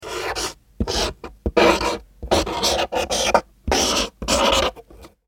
Звуки маркера
Шуршание маркера по картону при написании слов